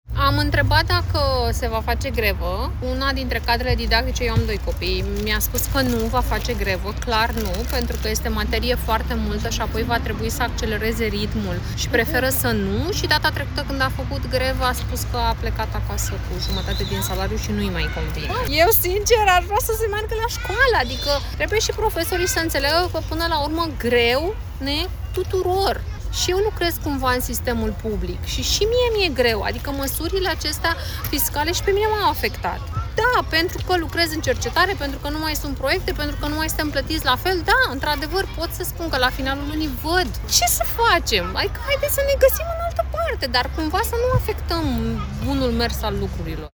Nu toate cadrele didactice participă la grevă în prima zi de școală: „Mi-a spus că este materie foarte multă și apoi va trebui să accelereze ritmul și preferă să nu”, precizează un alt părinte din București despre situația de la școala copiilor săi